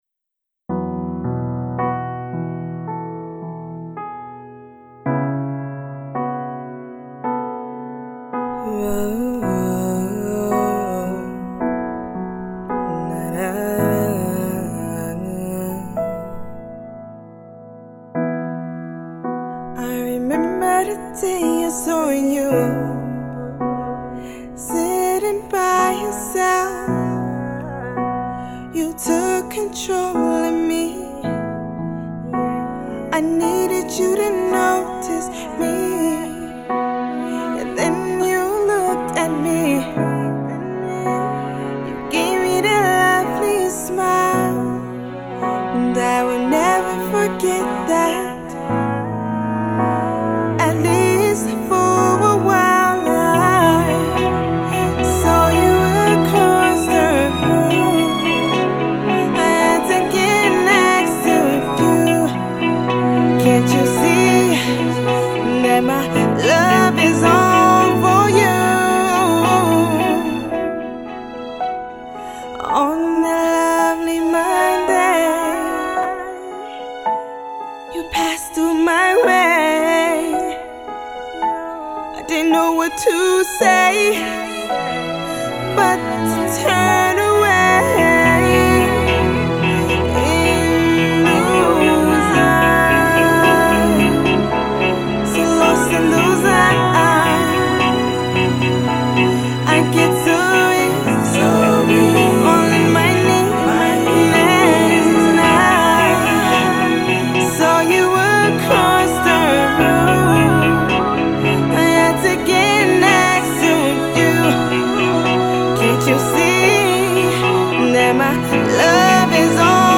is a somber love ballad